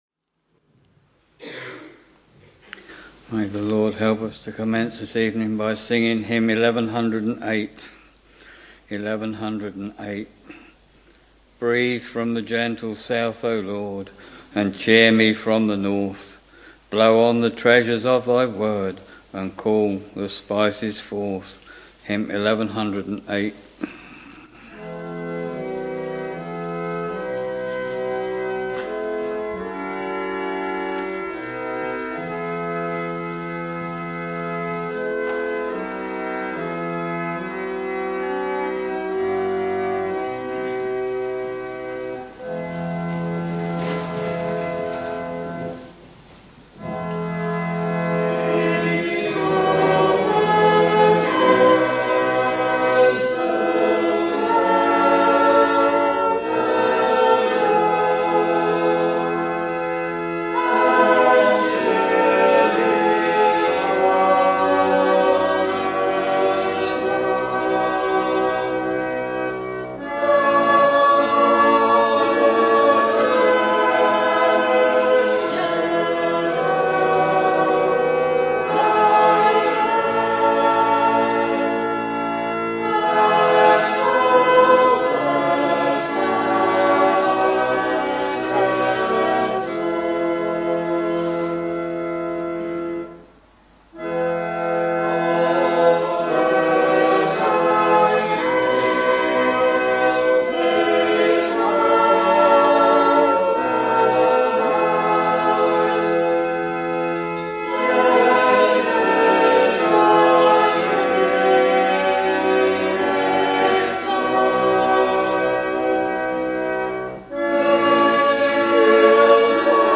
We are very pleased for you to listen to the live or archived services if you are not able to assemble for public worship in your local church or chapel.